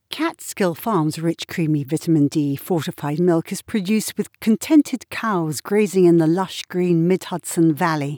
This is an 8 second stereo sound test.